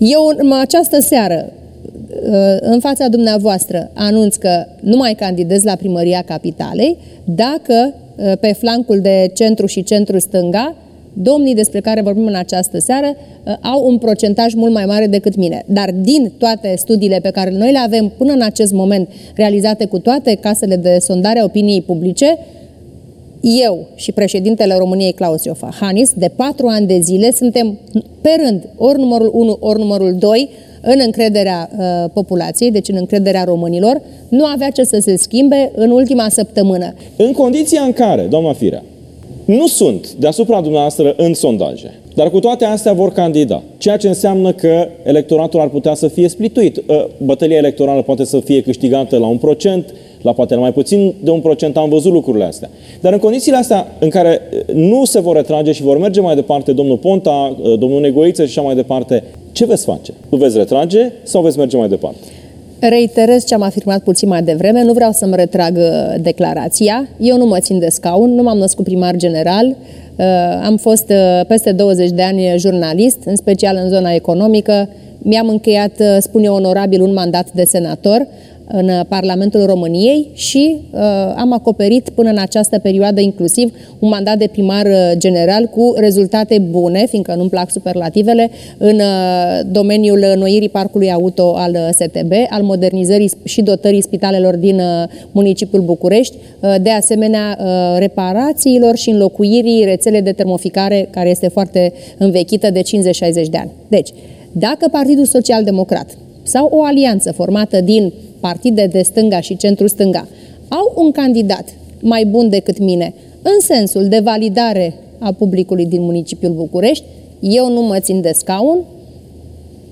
Într-un interviu la Antena 3, edilul a invocat, marți seara, o posibilă înţelegere între Victor Ponta şi Robert Negoiţă.